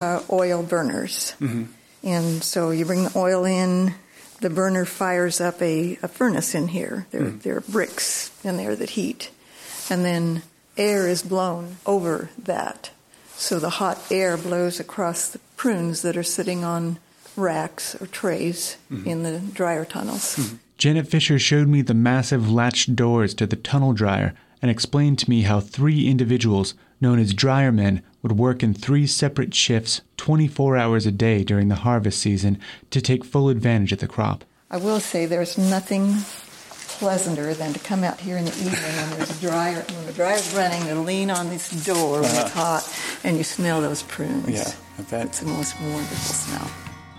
This excerpt was part of a public history series that I produced a while ago that explored the history of an area through labor, agriculture and architecture. This particular show was looking at the history of the Oregon prune industry (the ultimately defeated rival to California’s raisin industry) by researching a giant, animatronic bear covered in prunes that was a display for the industry as a part of the 1905 Lewis and Clark Centennial Exposition.